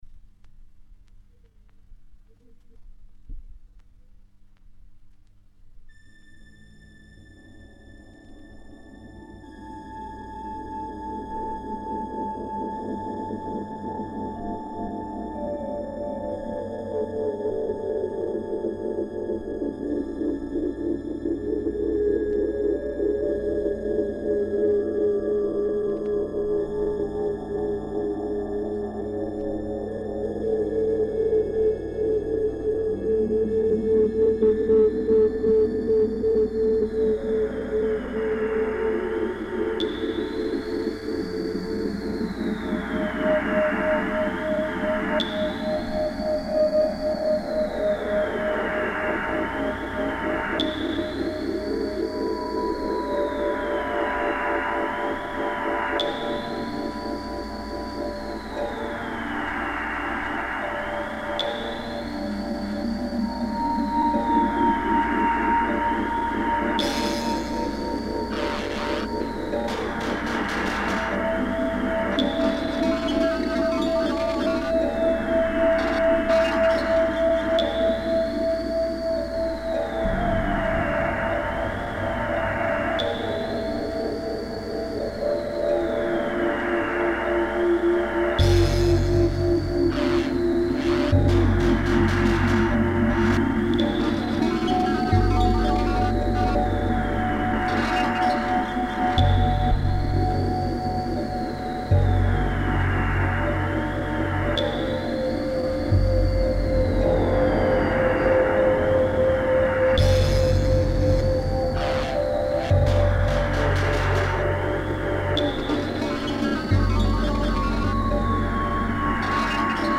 i've made a hauntology mix.